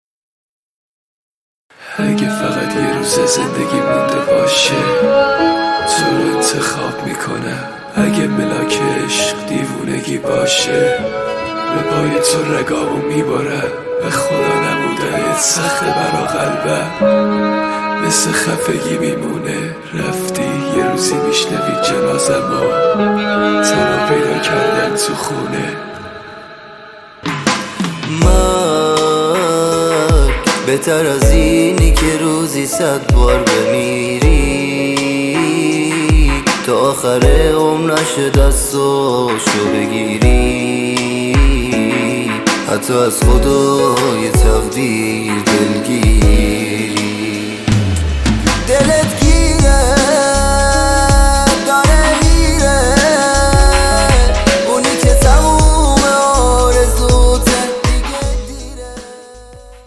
دیس لاو